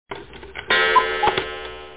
1 channel
cuckoo.mp3